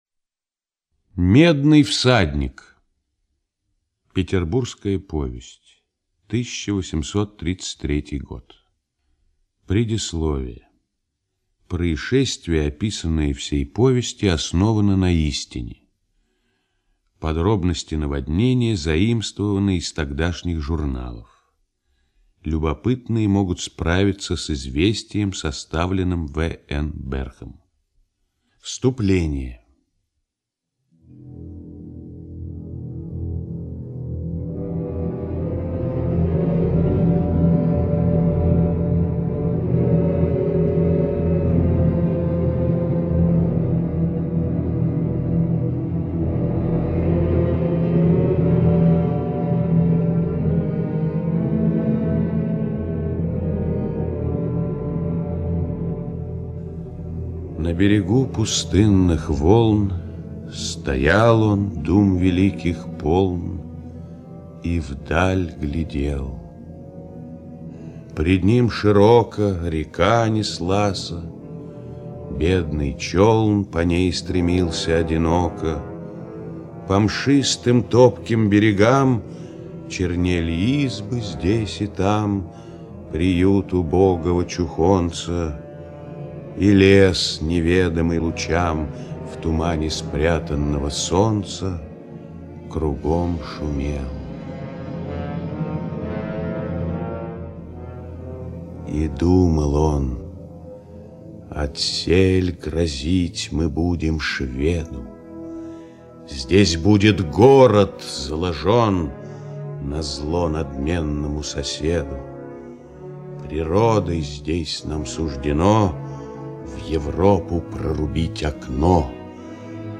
Медный всадник - аудио поэма Пушкина А.С. На берегу пустынных волн Стоял он, дум великих полн, И вдаль глядел.